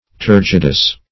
turgidous - definition of turgidous - synonyms, pronunciation, spelling from Free Dictionary Search Result for " turgidous" : The Collaborative International Dictionary of English v.0.48: Turgidous \Tur"gid*ous\ (t[^u]r"j[i^]d*[u^]s), a. Turgid.
turgidous.mp3